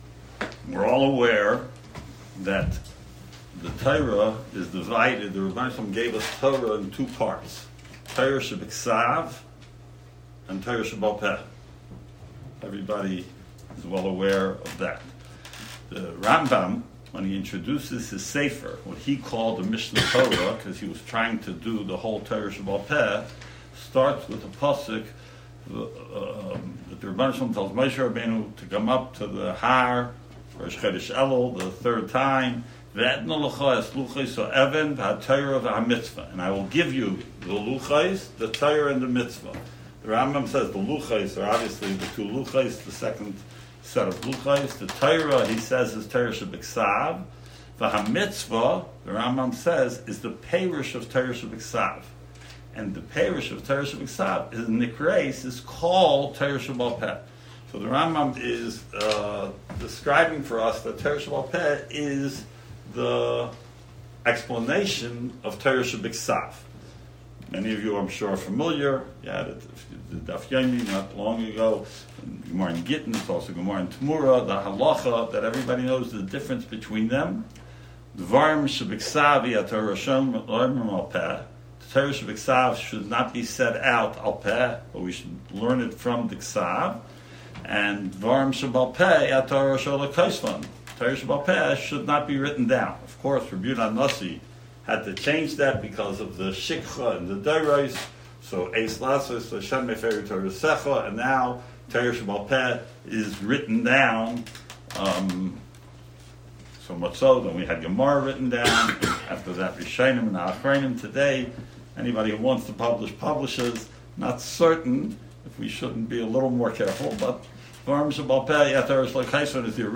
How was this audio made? On Tuesday, August 22nd, the Yeshiva hosted its inaugural Alumni event in the Teaneck/Bergenfield area.